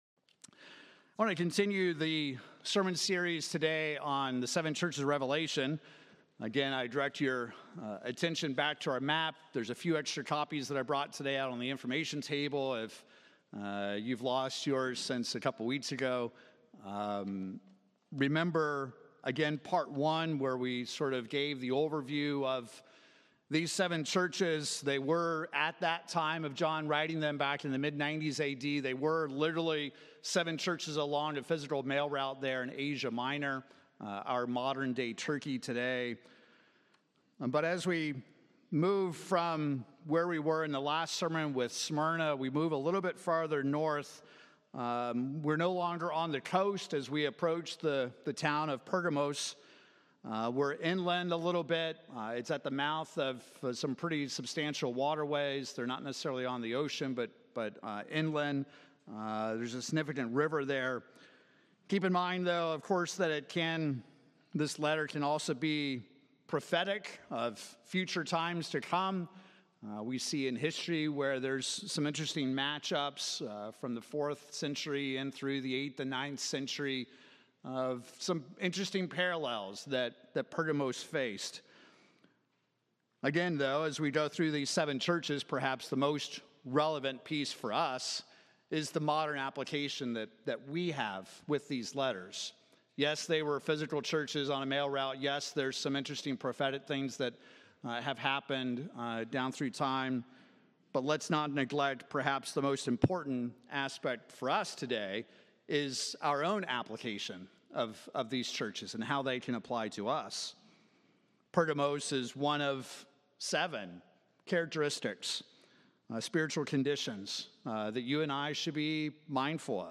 Their house is compared to Balaam and later the Nicolaitans. There are references to Satan’s throne and an admonishment to shape up or face the sword of His mouth. In this sermon we examine the congregation at Pergamos to ensure that we are not buying our desires.